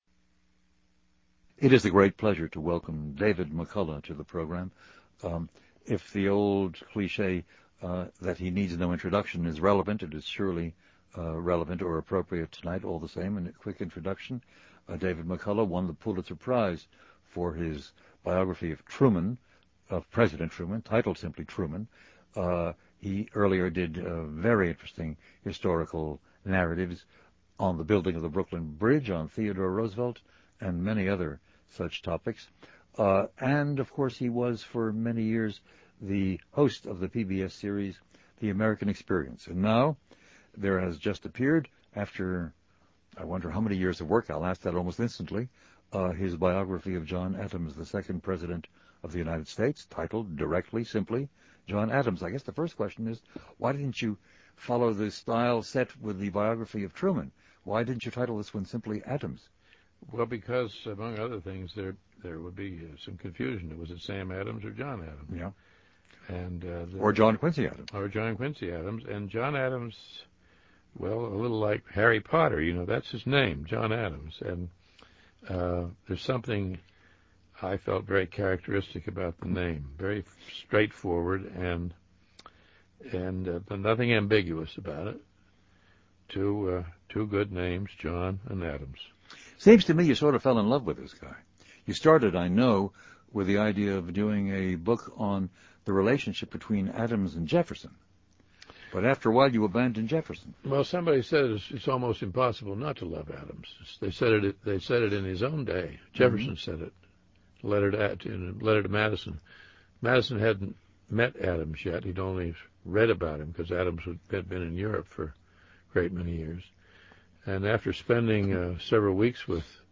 David McCullough’s biography of John Adams won the Pulitzer Prize and this discussion with him preceded the award by some months. John Adams was short, tubby, passionate, often angry and, in McCullough’s judgment, could never have won the presidency in the electronic age. How fortunate we were in having him as one of the “Founders” – and as President – is one of the main themes of this chat.